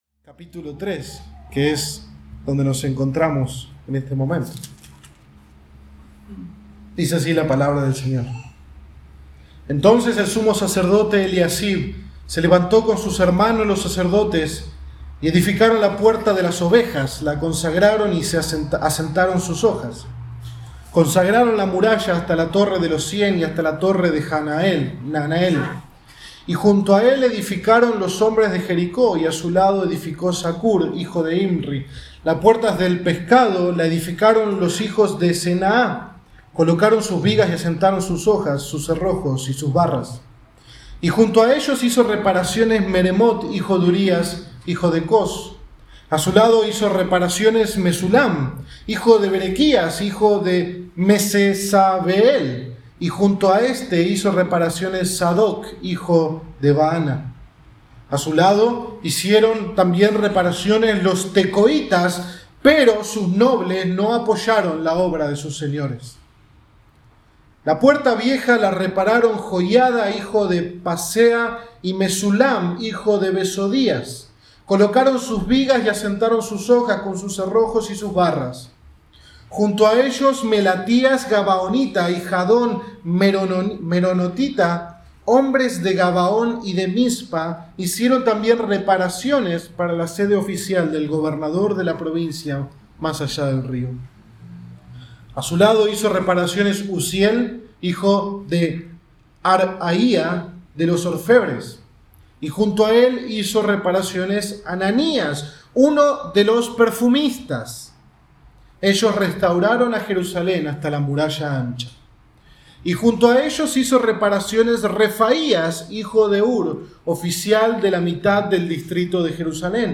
octubre 18, 2020 Sermón ¡Todas las manos a la obra!